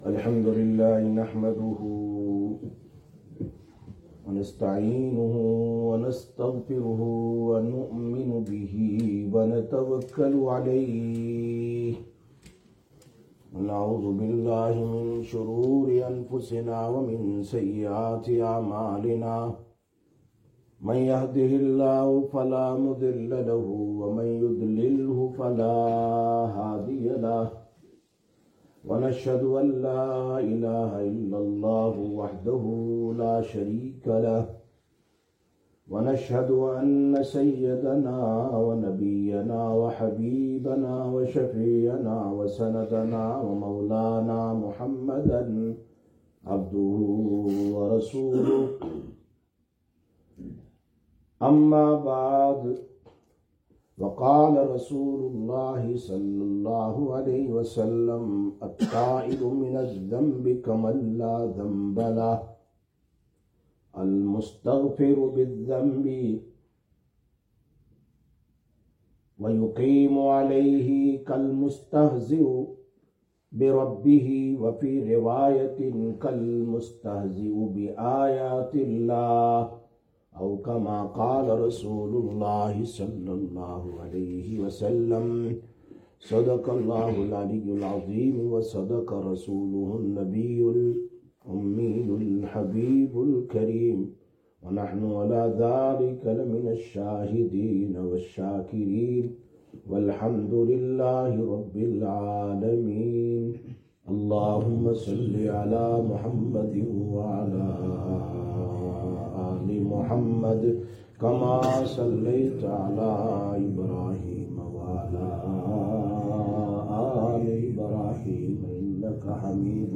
17/01/2025 Jumma Bayan, Masjid Quba